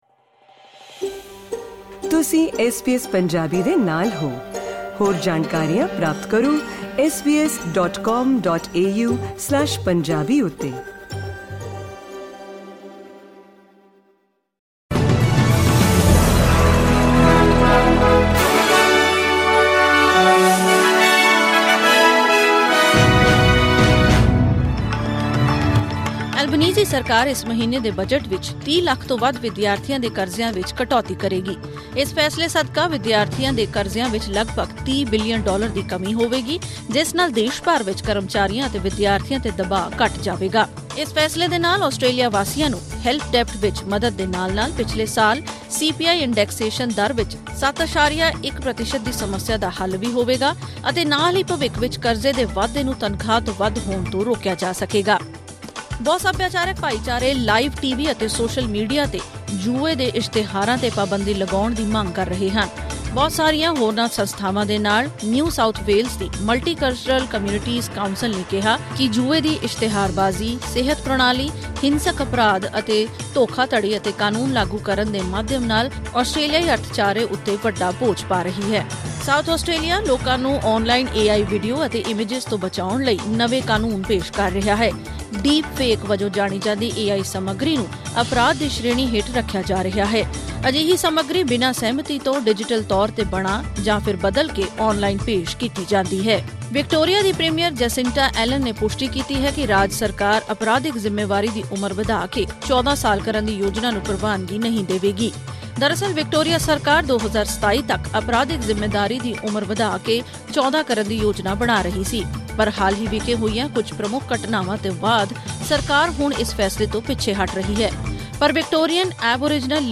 ਐਸ ਬੀ ਐਸ ਪੰਜਾਬੀ ਤੋਂ ਆਸਟ੍ਰੇਲੀਆ ਦੀਆਂ ਮੁੱਖ ਖ਼ਬਰਾਂ: 15 ਅਗਸਤ 2024